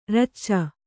♪ racca